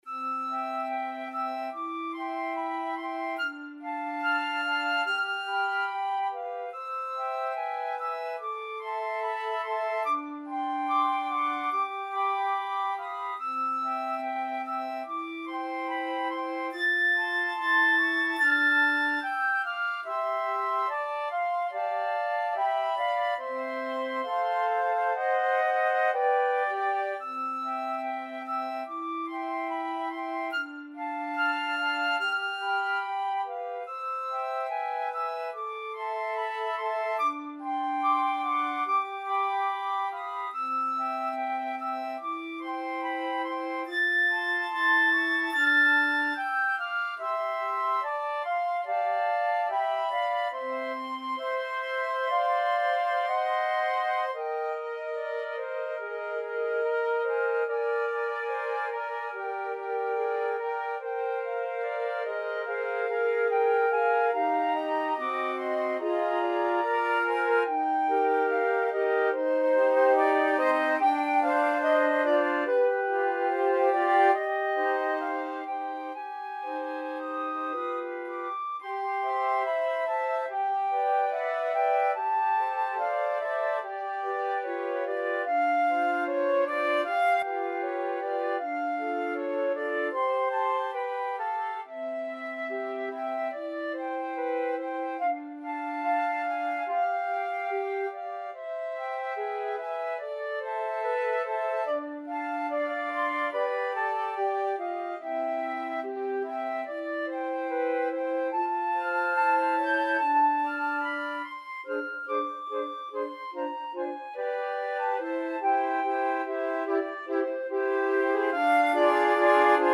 Free Sheet music for Flute Quartet
4/4 (View more 4/4 Music)
C major (Sounding Pitch) (View more C major Music for Flute Quartet )
Andantino =72 (View more music marked Andantino)
Classical (View more Classical Flute Quartet Music)